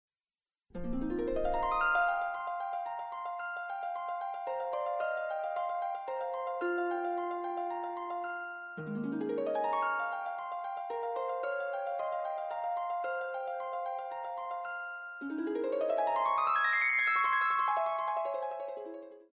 is for solo pedal harp
Its form incorporates elements of the Baroque dance suite.